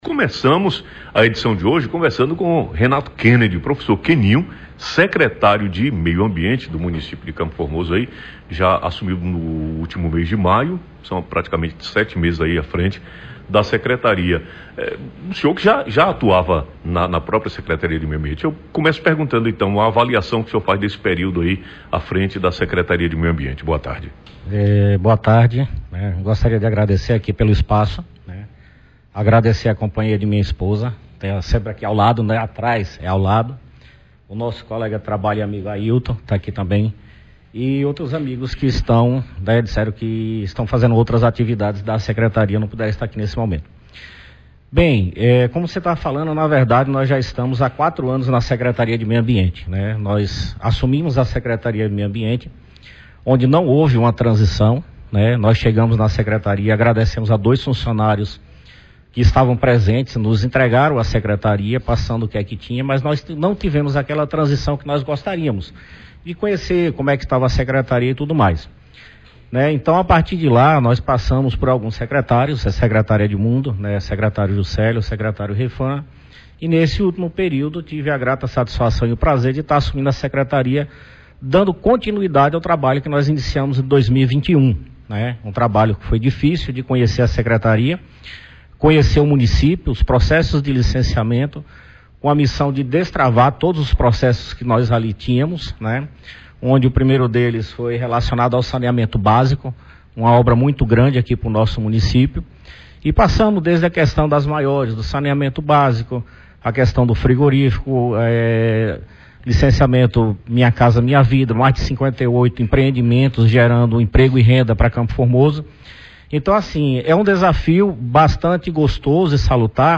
Entrevista ao vivo com o secretário de meio ambiente, Renato Kennedy